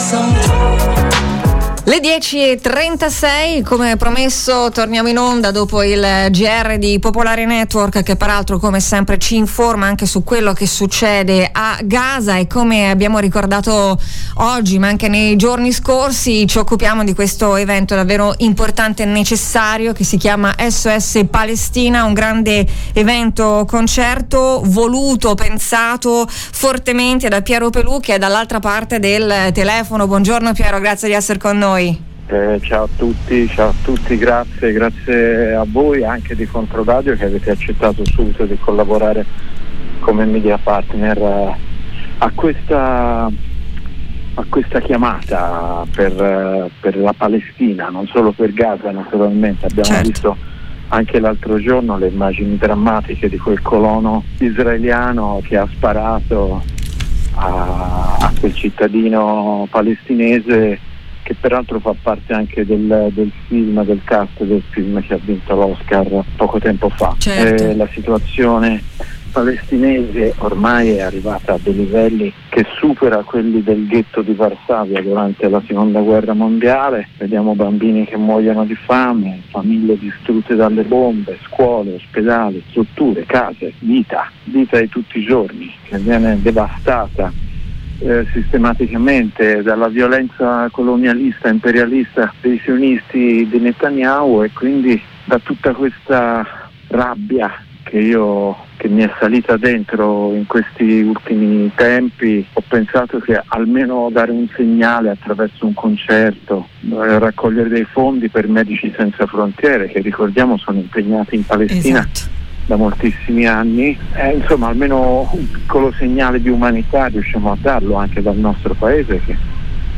S.O.S. Palestina! Il grande evento ideato da Piero Pelù. Ascolta l'intervista.
Ne abbiamo parlato ai nostri microfoni con l’ideatore, Piero Pelù.